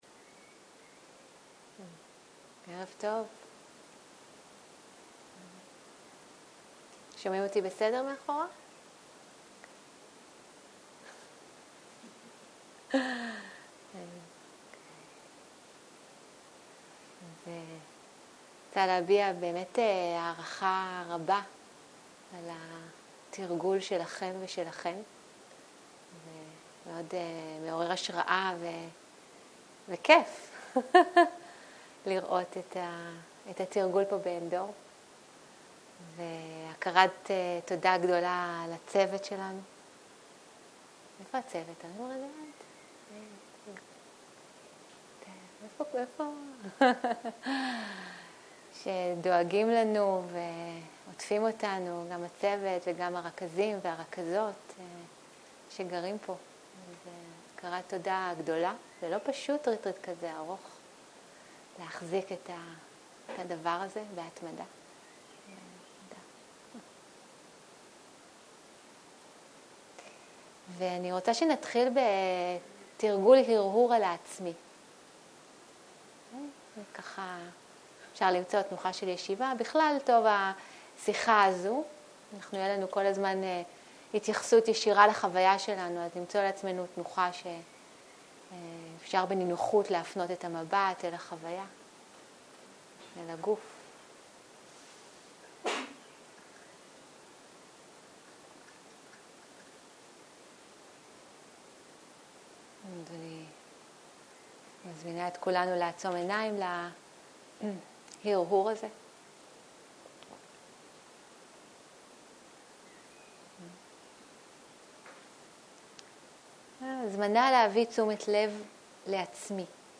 ערב - שיחת דהרמה - אנאטה ו-5 המצרפים